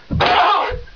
j_pain1.wav